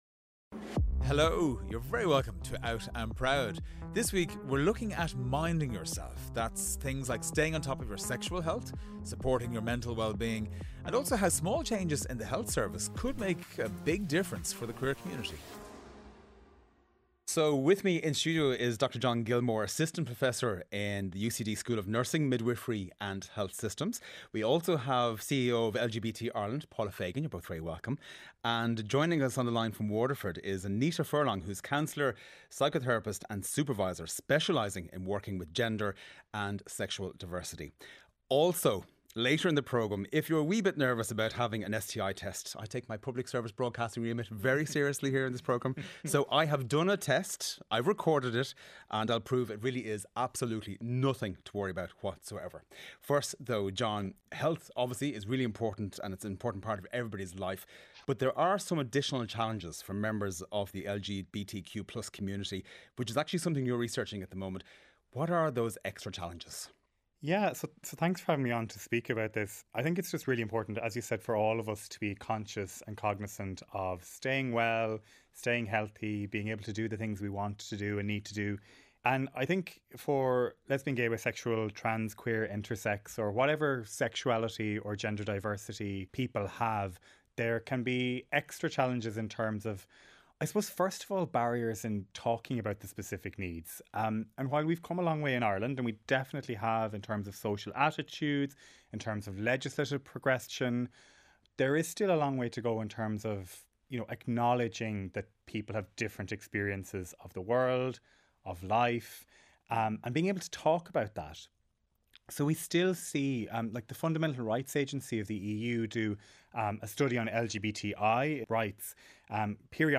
8am News Bulletin - 10.07.2024